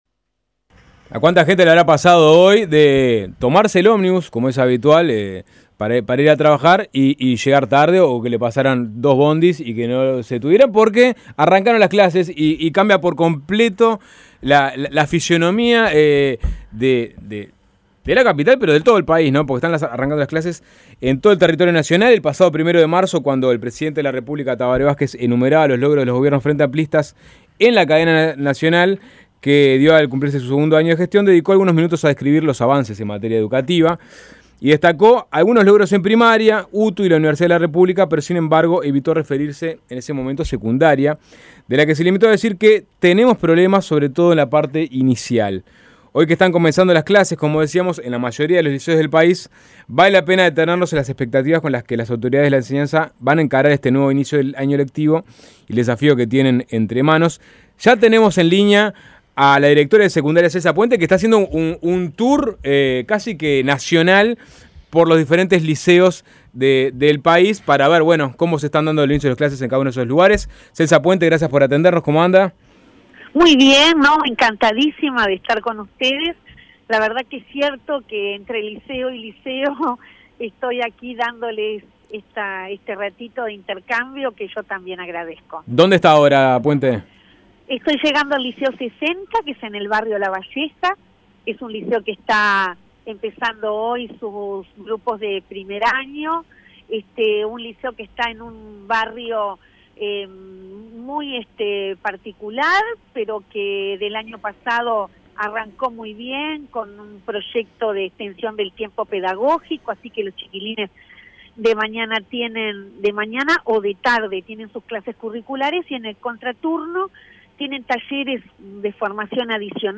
La directora general del Consejo de Educación Secundaria, Celsa Puente, dijo en Suena Tremendo que la propuesta presentada por el senador Luis Lacalle Pou de abrir un bachillerato público este año en la cuenca de Casavalle, ya está contemplada en el plan educativo.